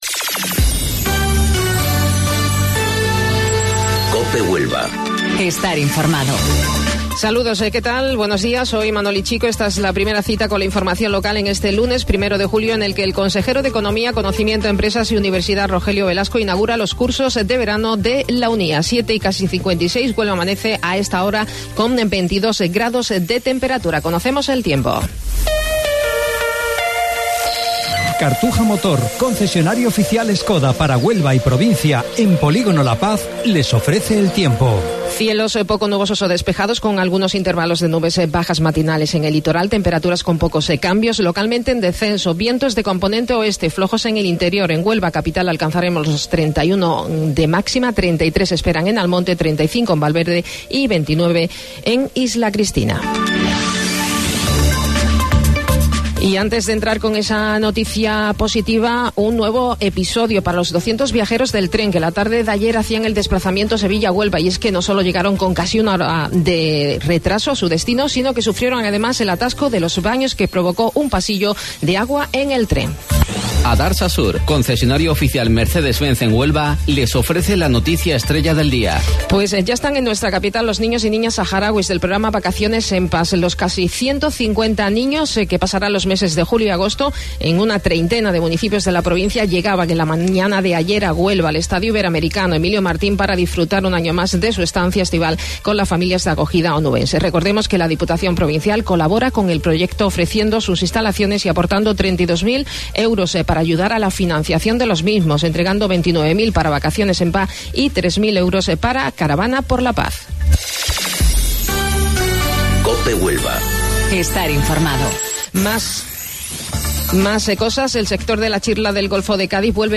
AUDIO: Informativo Local 07:55 del 1 de Julio